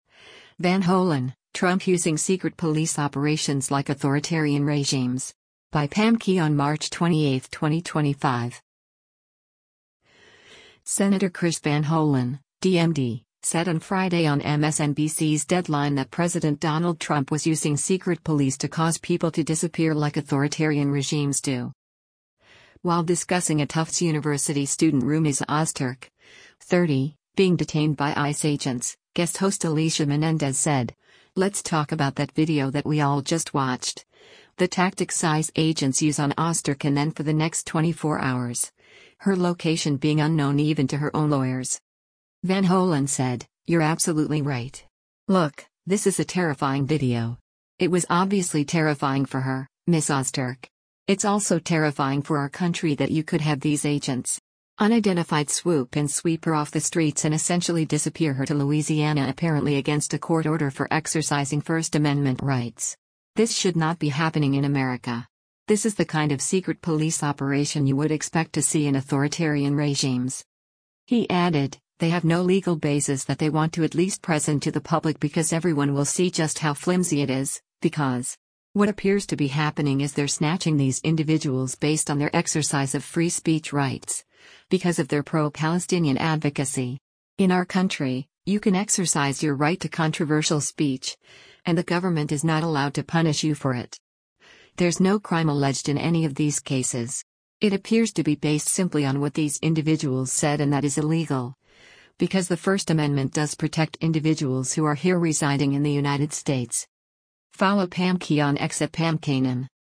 Senator Chris Van Hollen (D-MD) said on Friday on MSNBC’s “Deadline” that President Donald Trump was using “secret police” to cause people to “disappear” like authoritarian regimes do.